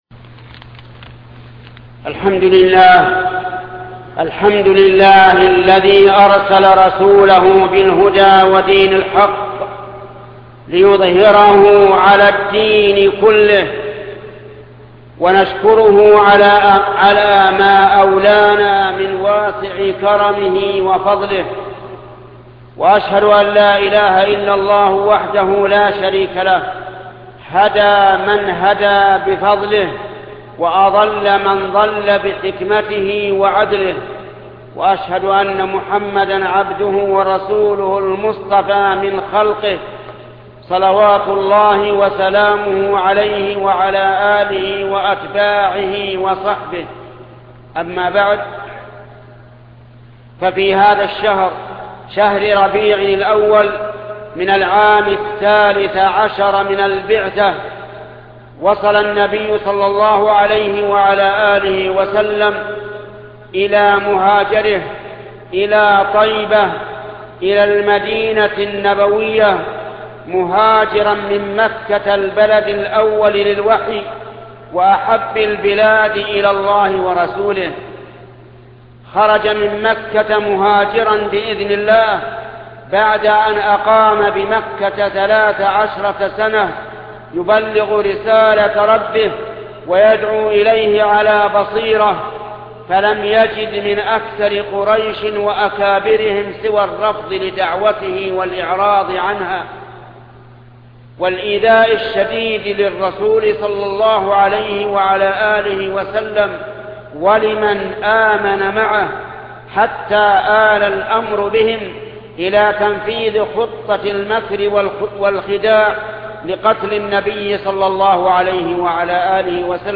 خطبة هجرة النبي صلى الله عليه وسلم الشيخ محمد بن صالح العثيمين